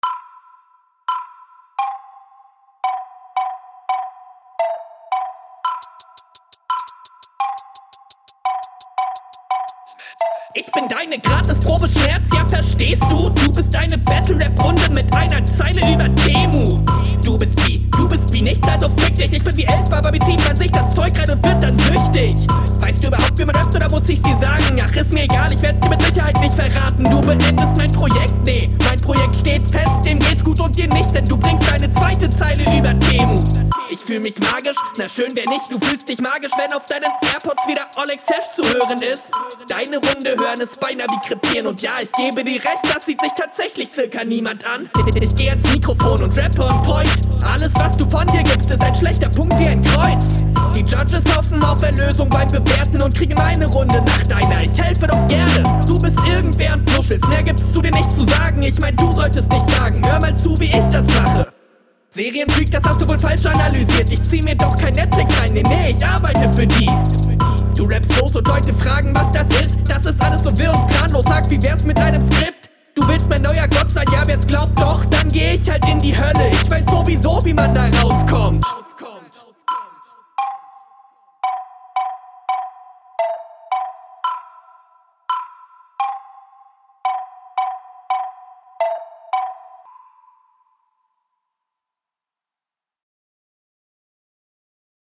Soundquali erneut besser, auch der Flow ist überlegen, da sind ein paar sehr cleane Shuffles …